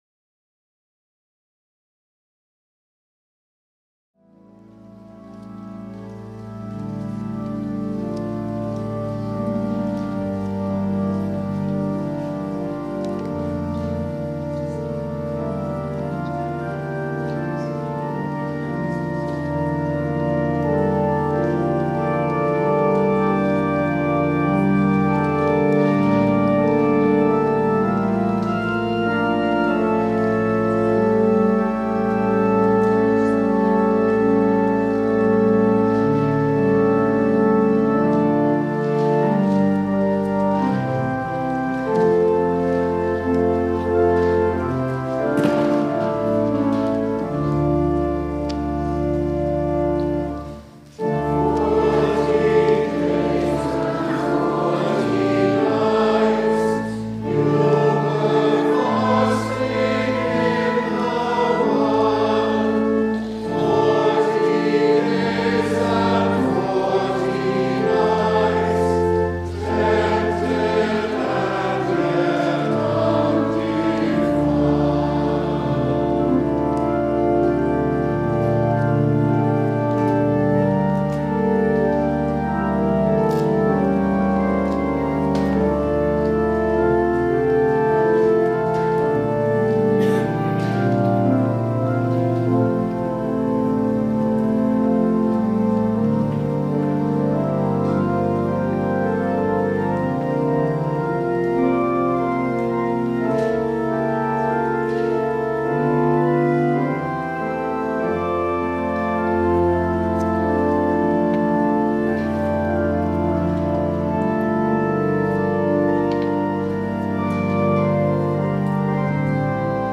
Worship and Sermon audio podcasts
Podcast from Christ Church Cathedral Fredericton
WORSHIP - 10:30 a.m. First Sunday in Lent